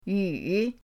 yu3.mp3